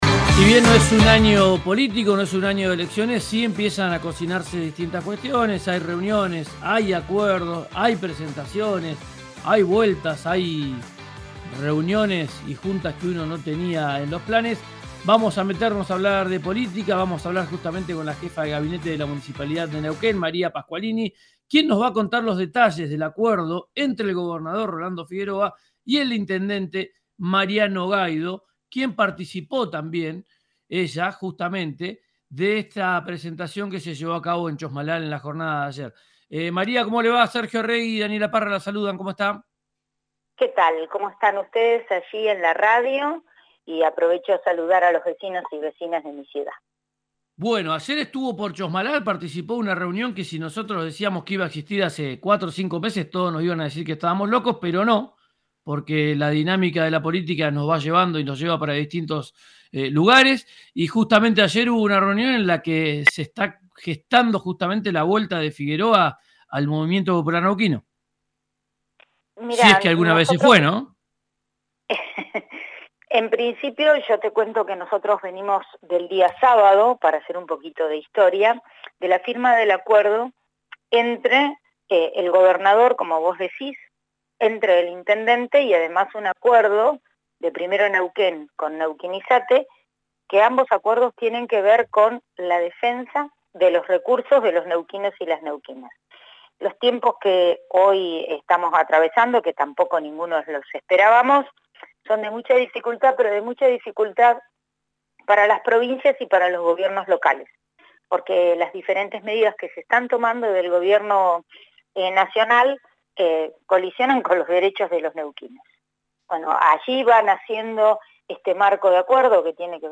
La jefa de gabinete de la ciudad de Neuquén, María Pasqualini, pasó por el aire de RIO NEGRO RADIO para contar los detalles de la alianza entre el intendente Mariano Gaido y el gobernador Rolando Figueroa. Habló de la necesidad de que los desacuerdos entre fuerzas políticas se pongan en segundo plano para defender los intereses de Neuquén.